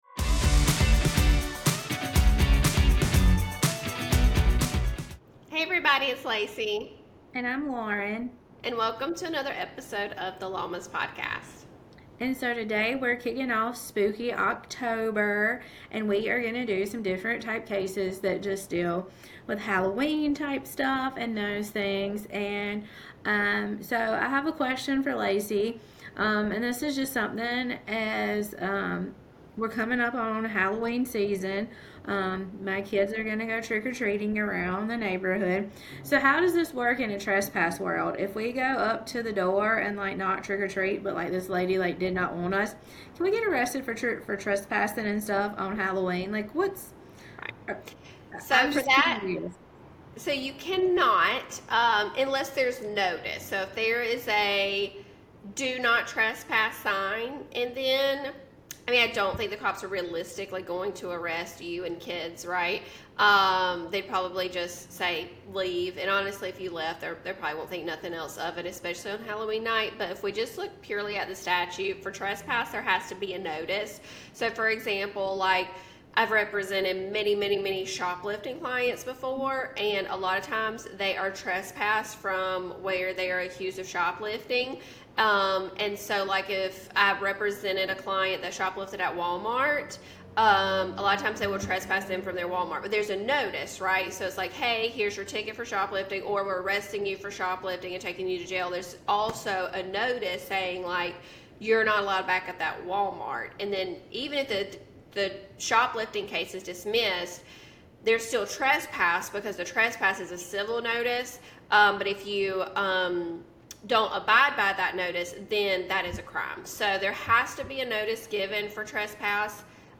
The conversation mixes legal analysis with personal stories, from family costumes to unexplained encounters that bring both laughs and chills.